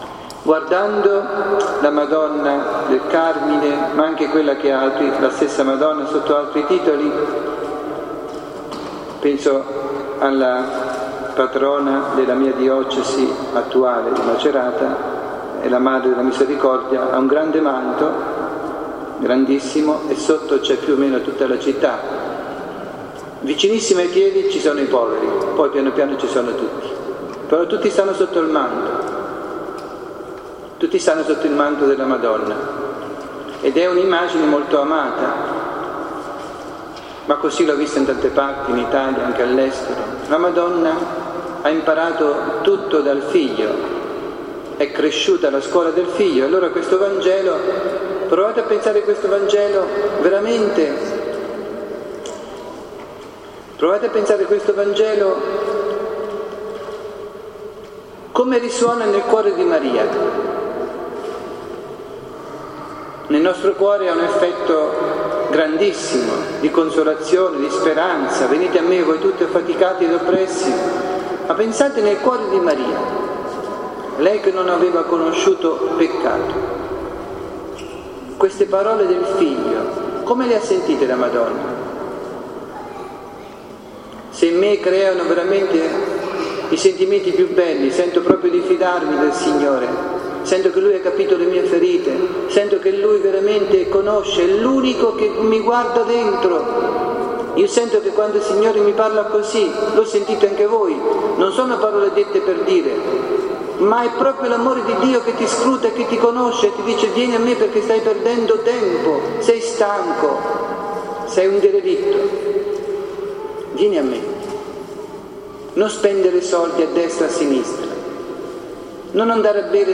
Omelia
nella Basilica di Santa Maria del Carmine (FI)